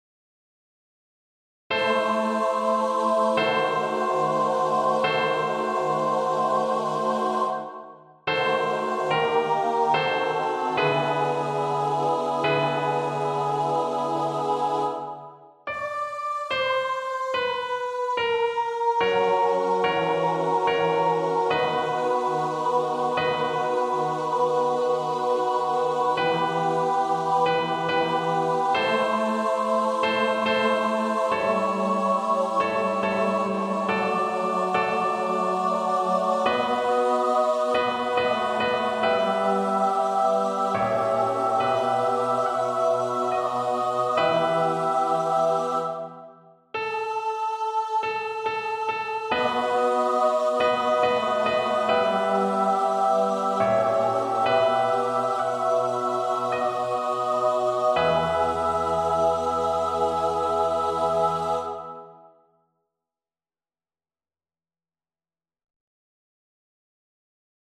Qui tollis peccata mundi (Gloria RV 589) Choir version
Choir  (View more Intermediate Choir Music)
Classical (View more Classical Choir Music)